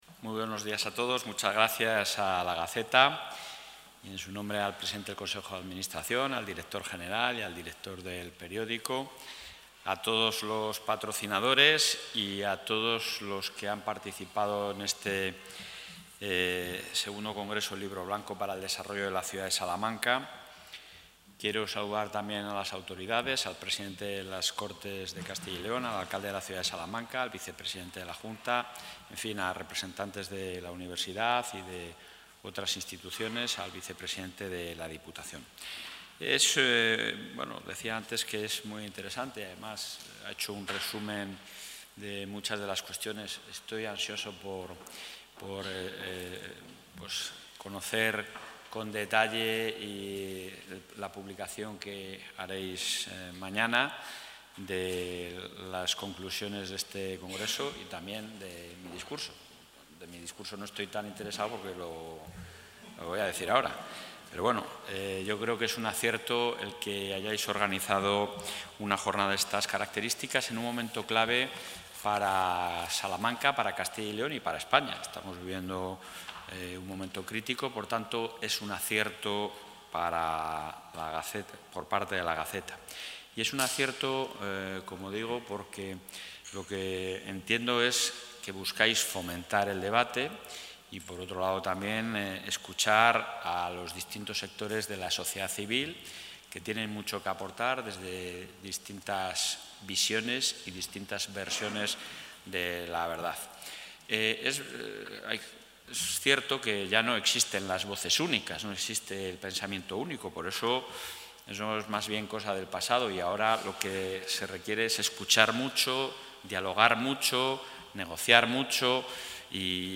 Intervención presidente.